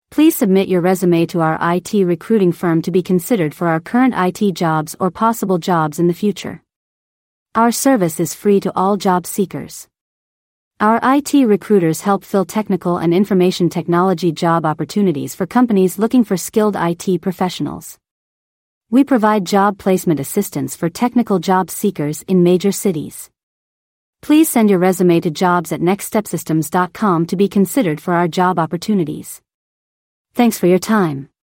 Job Seekers, Listen to Our Artificial Intelligence (AI) on How Our IT Staffing Company Can Help You with The Next Step in Your IT Careers
Please take a moment to listen to an audio file about how our IT staffing company can help job seekers with the next step in their careers generated by Artificial Intelligence (AI). We are recruiting for a wide range of roles including Software Developers, Software Engineers, DBAs, Data Engineers, Systems Administrators, Network Engineers, Sales, Management, IT Executives, and CEOs.